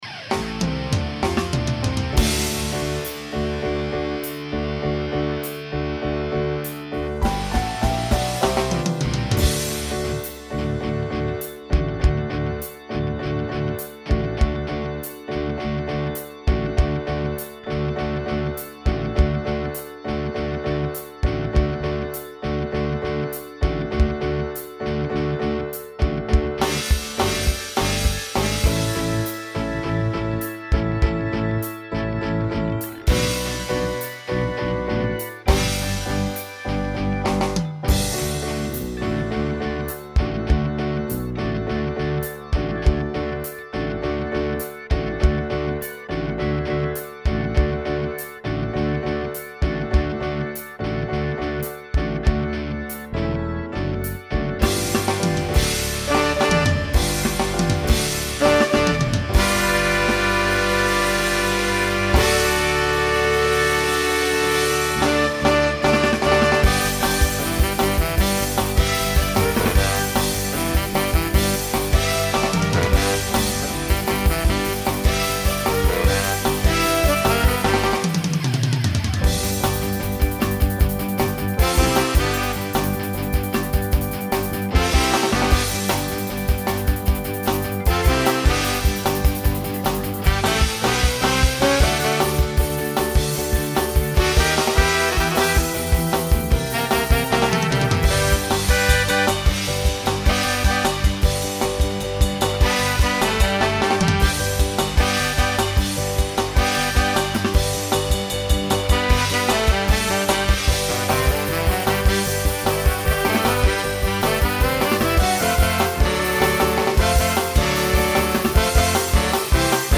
New TTB voicing for 2025.